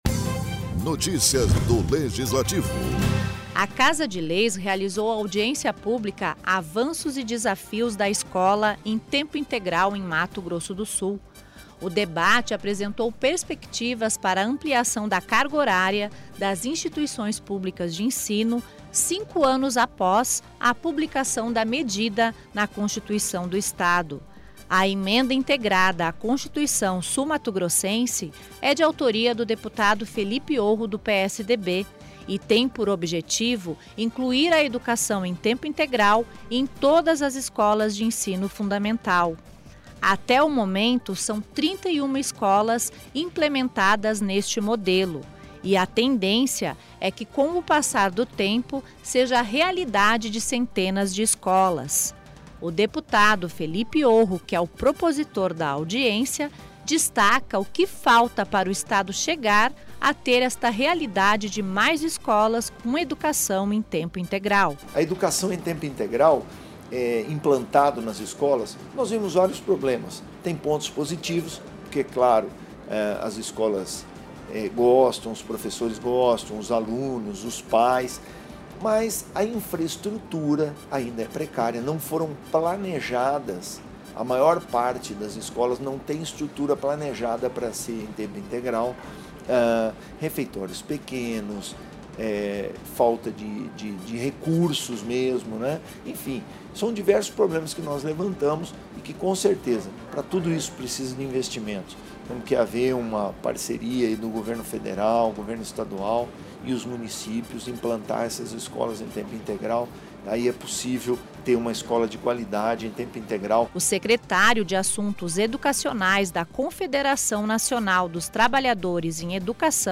Audiência pública debate os avanços e desafios da escola em tempo integral no MS